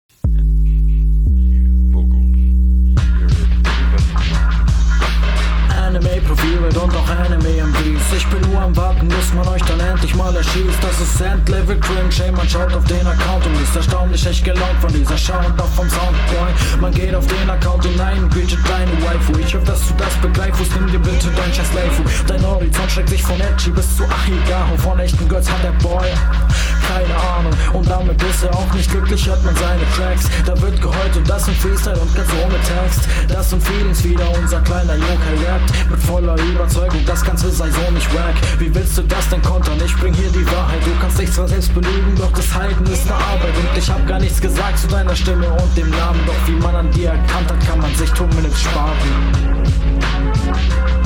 Okay fedder Subbase, und damn der Beat ist cool, wäre der bass mit der kick …
Cooler Beat.